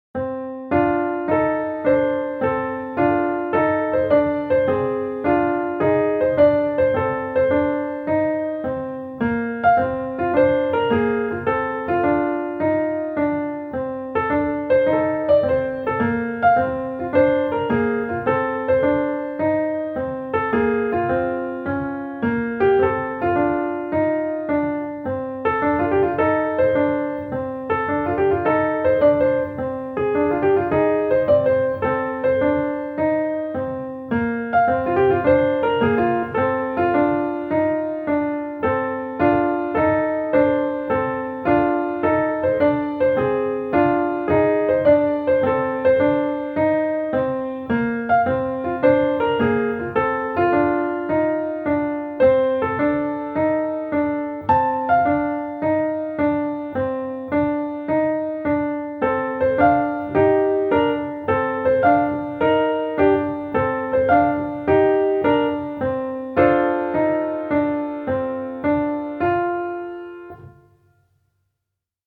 Piano Only